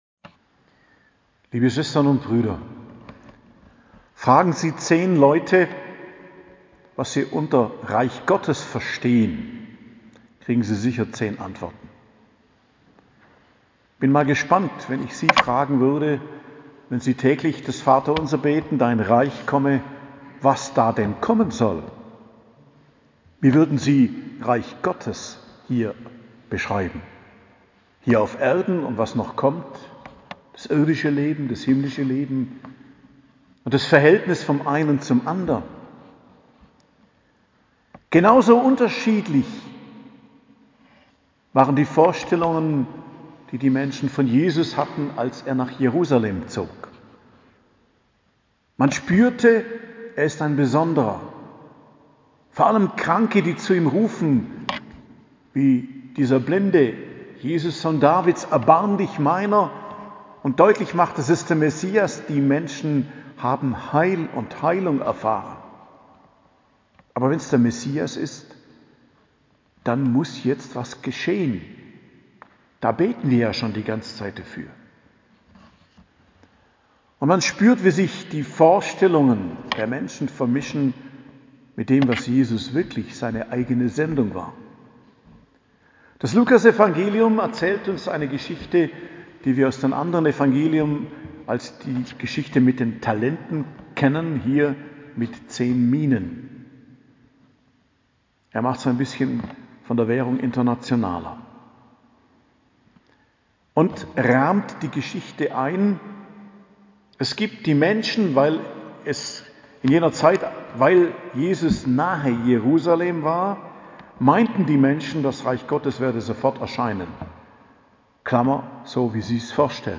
Predigt am Mittwoch der 33. Woche im Jahreskreis, 17.11.2021